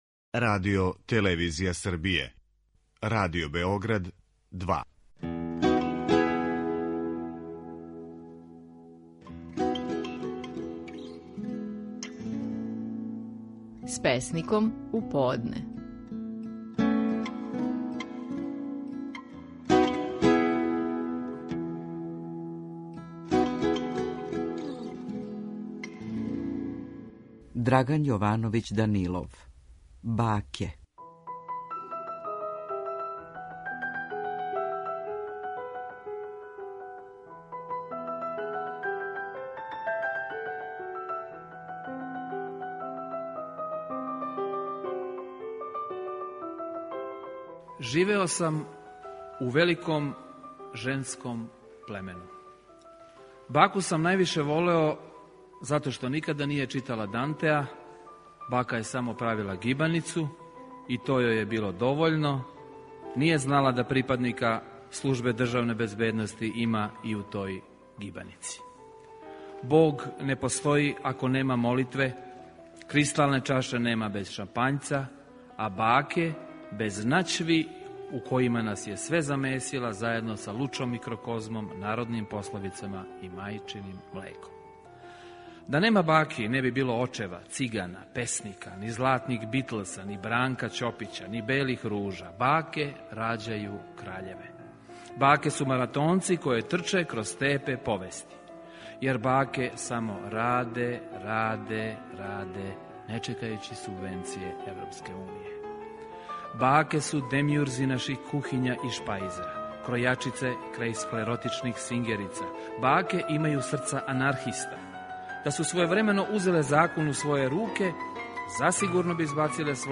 Стихови наших најпознатијих песника, у интерпретацији аутора.